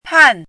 chinese-voice - 汉字语音库
pan4.mp3